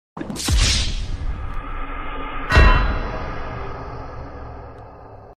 Звук смерти от кринжа в GTA